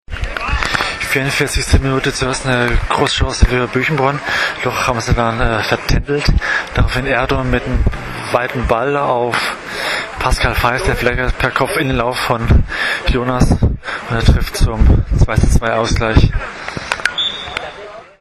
O-Ton: